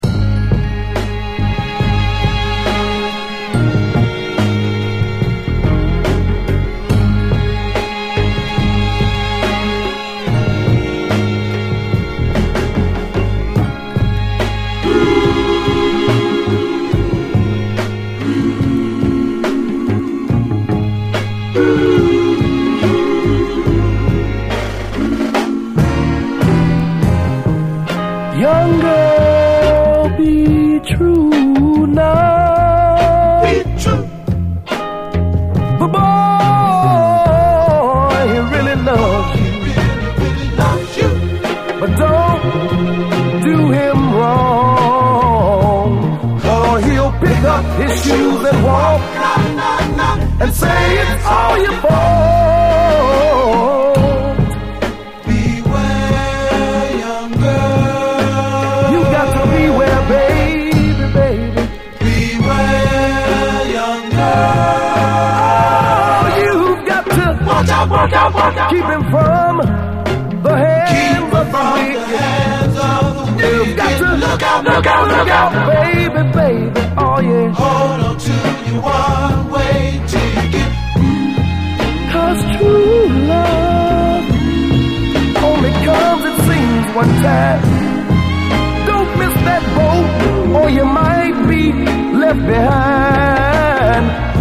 キラー・レアグルーヴ〜モダン・ソウル盤
イントロのギター・カッティングから風格漂うインスト・レアグルーヴ・トラック
３拍子に転調する展開も超カッコいい
冷たいギターの音色のアーバン・メロウ・ソウル